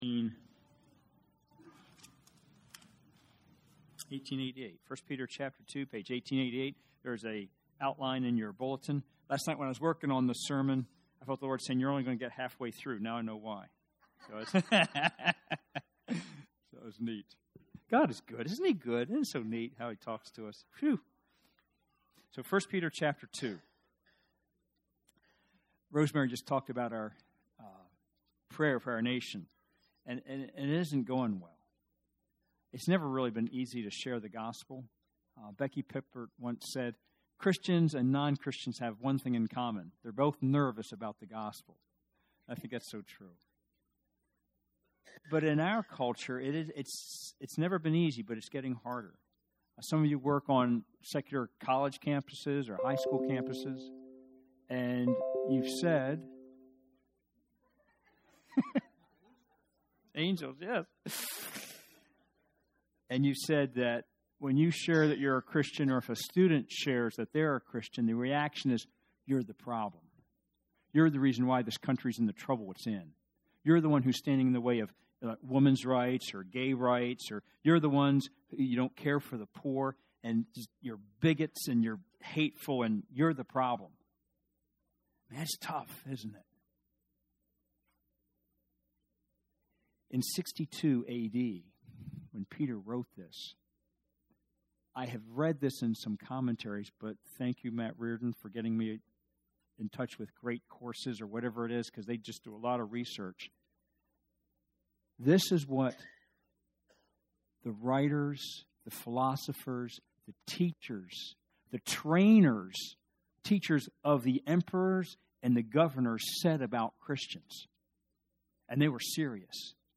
1 Peter 2:13-15 Sermon